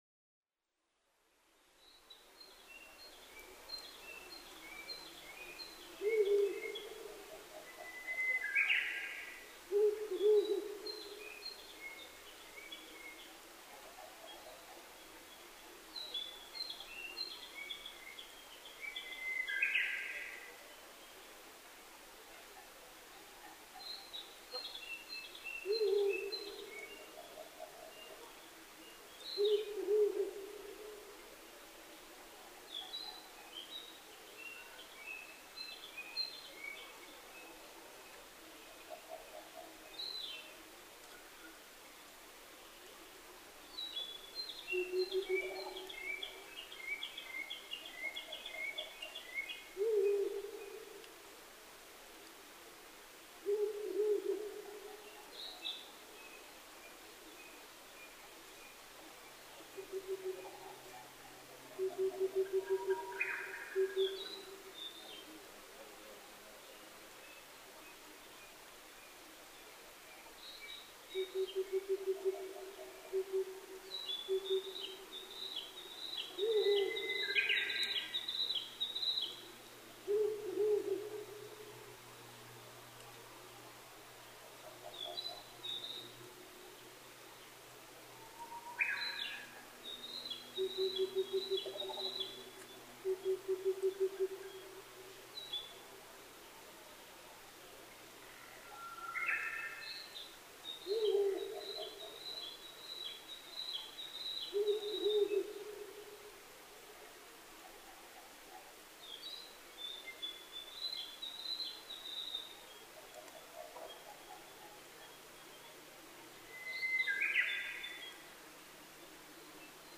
フクロウ　Strix uralensisフクロウ科
日光市細尾　alt=1150m
MPEG Audio Layer3 FILE 128K 　2'15''Rec: SONY PCM-D50
Mic: Panasonic WM-61A  Binaural Souce with Dummy Head
他の自然音：キビタキ、ウグイス、タゴガエル、ツツドリ